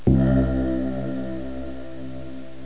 ding8.mp3